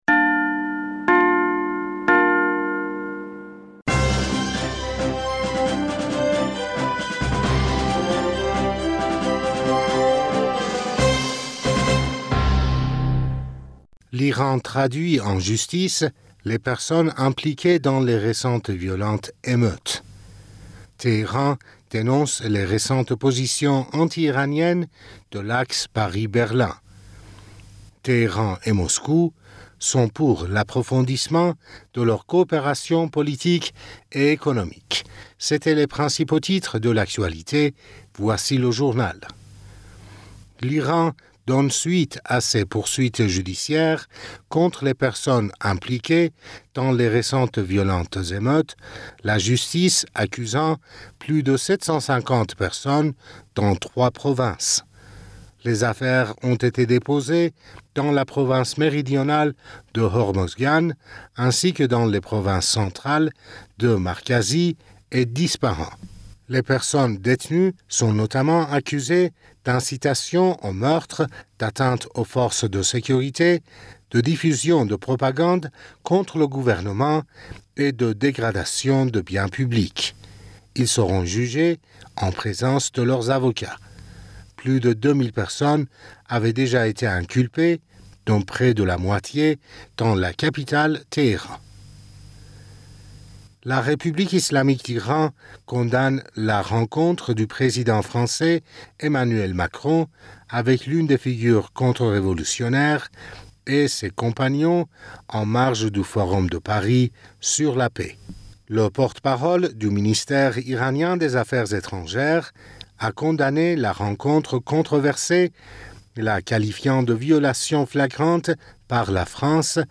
Bulletin d'information du 13 Novembre